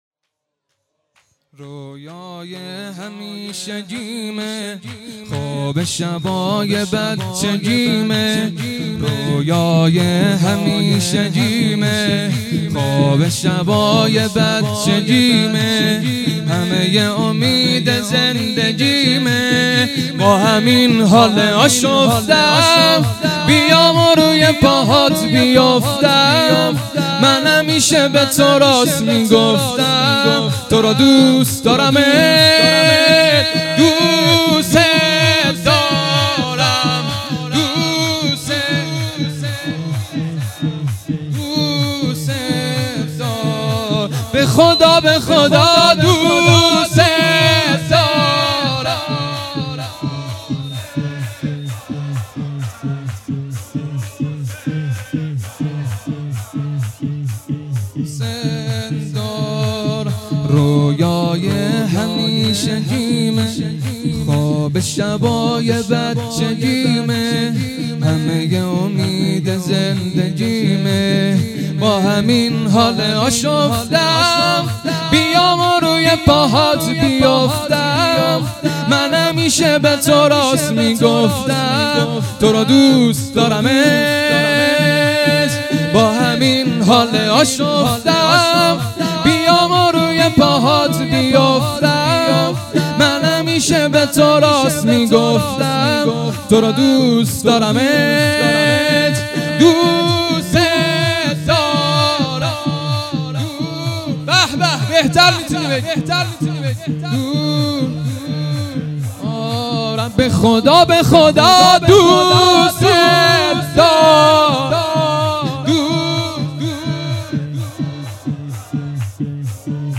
خیمه گاه - هیئت انصارالمهدی(عج) درچه - شور | رویای همیشگیمه
هیئت انصارالمهدی(عج) درچه